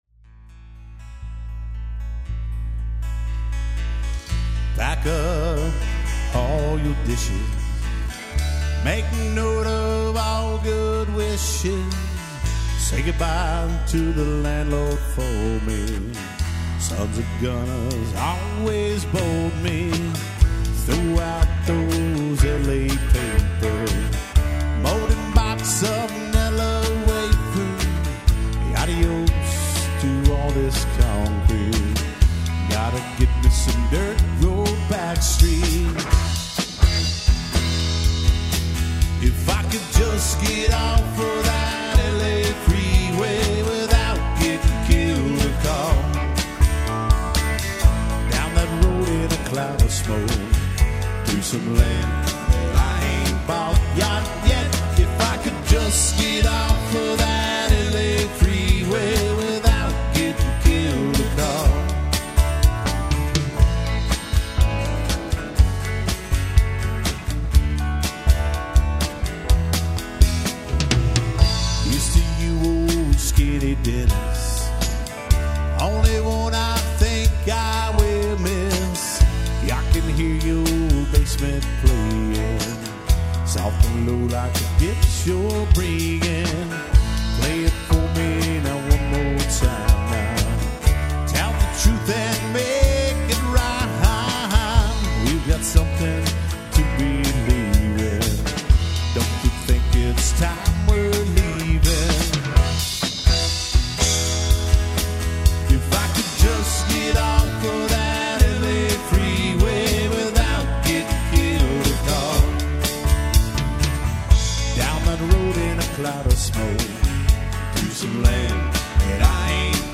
Here are a couple of songs we played at a recent wedding.
live, at Nutty Brown
classic Texas country music, plus a little rock and/or roll